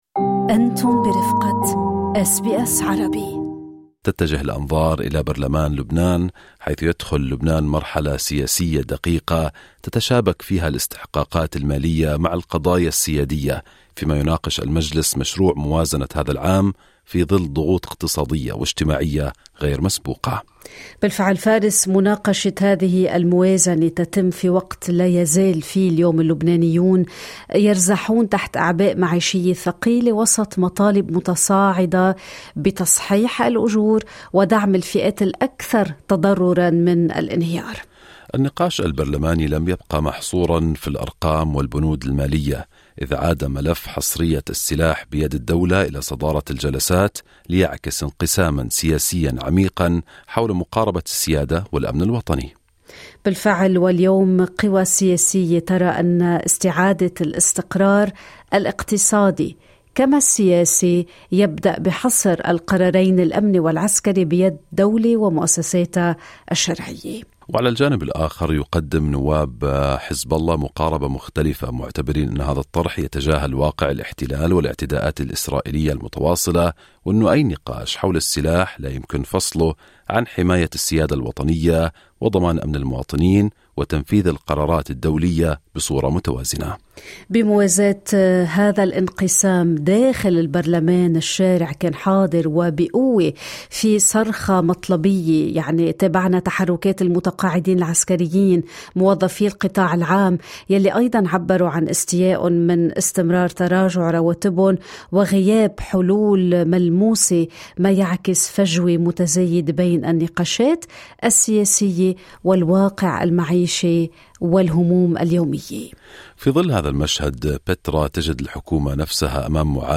تحدثنا مع النائب إيهاب مطر ونقل لنا الصورة من قلب الحدث.